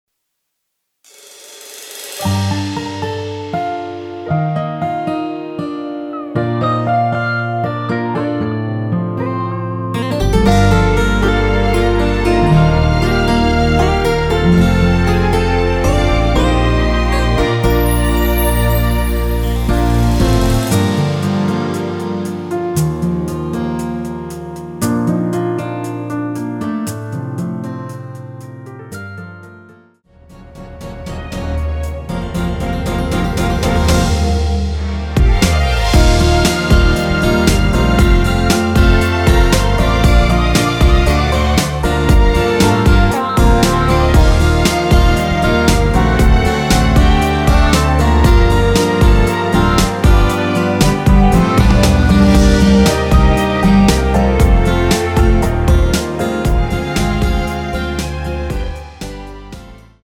원키에서(-2)내린 MR입니다.(미리듣기 참조)
Gm
앞부분30초, 뒷부분30초씩 편집해서 올려 드리고 있습니다.